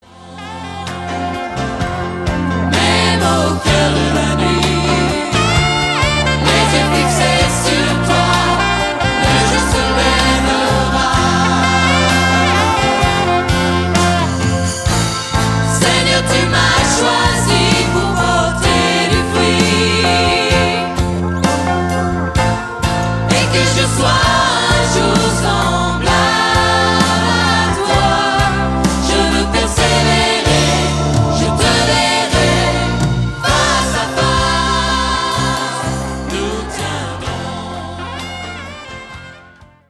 des chants dynamiques enregistrés en public.